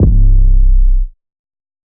808_Oneshot_Trendy_C.wav